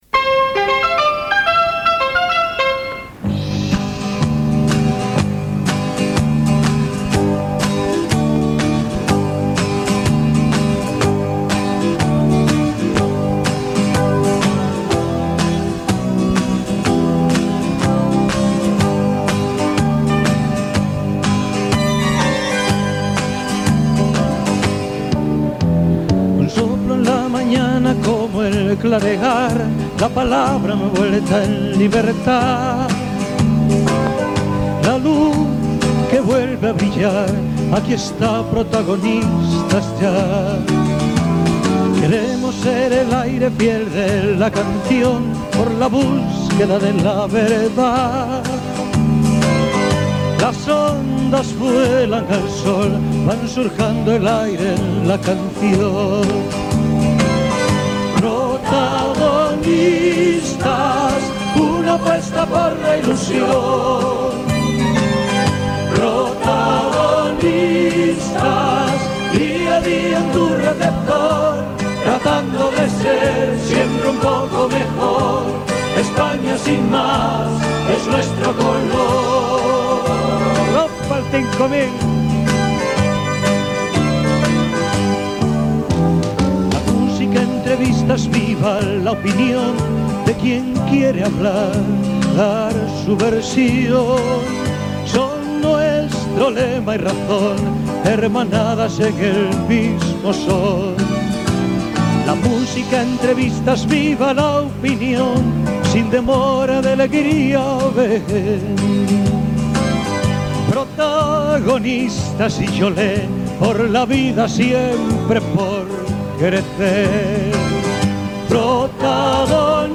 Programa 5000, fet des del Palau de la Música Catalana.
Info-entreteniment